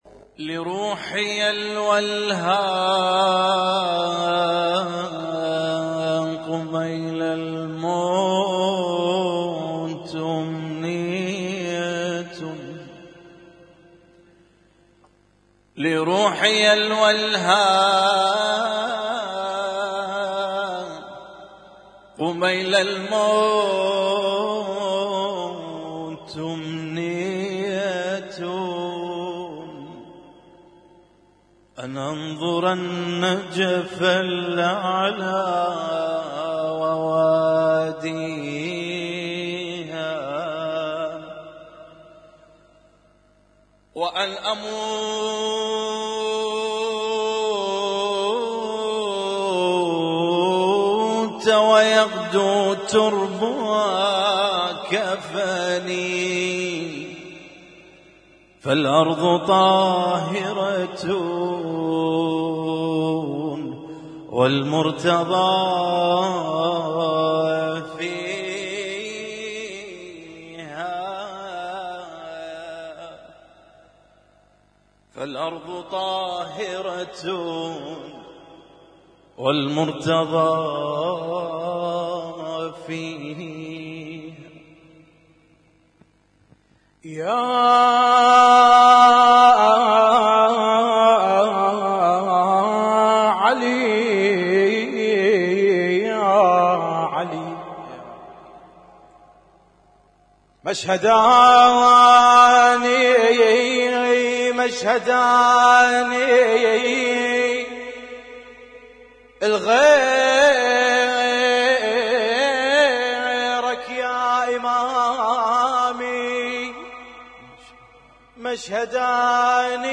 Husainyt Alnoor Rumaithiya Kuwait
الرواديد